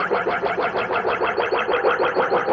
RI_DelayStack_95-03.wav